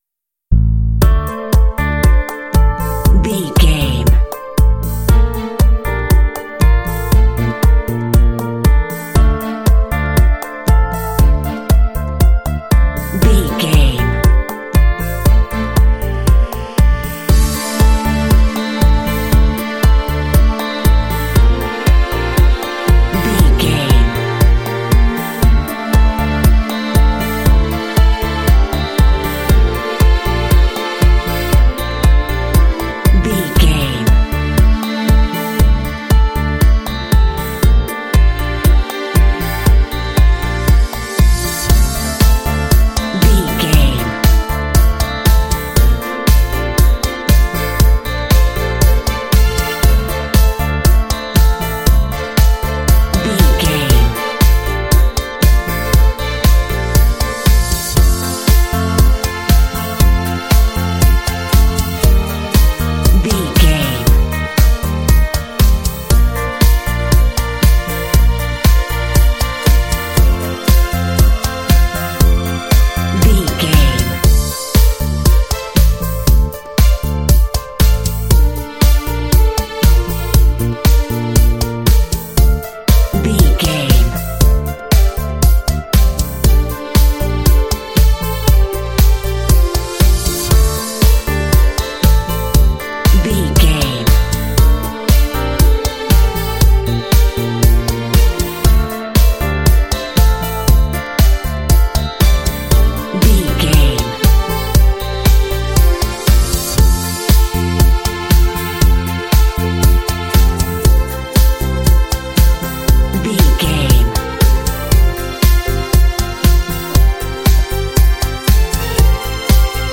Aeolian/Minor
uplifting
motivational
drums
synthesiser
strings
bass guitar
electric piano
electric guitar
synth-pop
indie